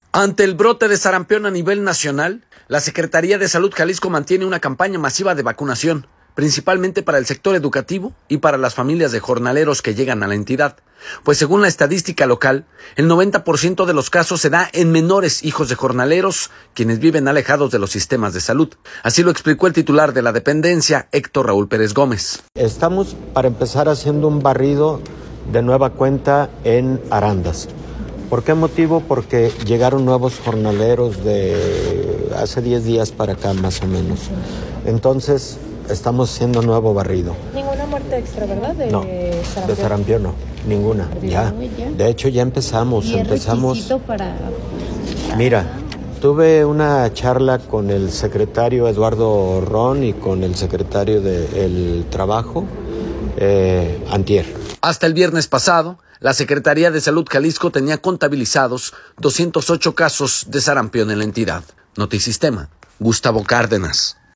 audio Ante el brote de sarampión a nivel nacional, la Secretaría de Salud Jalisco mantiene una campaña masiva de vacunación, principalmente para el sector educativo y para las familias de jornaleros, pues según la estadística local, el 90 por ciento de los casos se da en menores hijos de jornaleros quienes vive alejados de los sistemas de salud, así lo explicó el titular de la dependencia, Héctor Raúl Pérez Gómez.